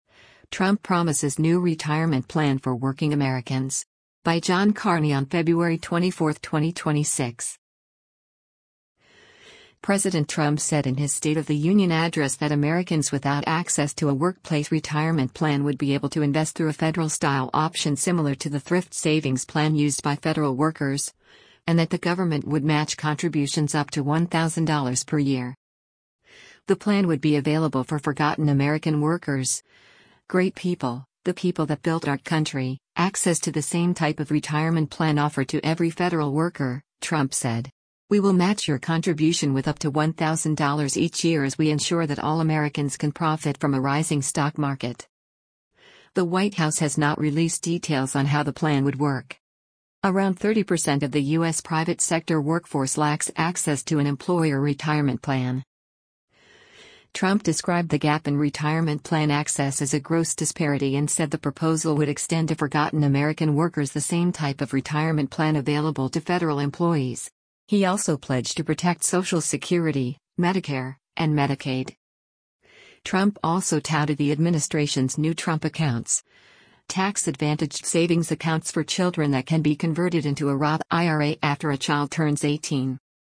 President Trump said in his State of the Union address that Americans without access to a workplace retirement plan would be able to invest through a federal-style option similar to the Thrift Savings Plan used by federal workers, and that the government would match contributions up to $1,000 per year.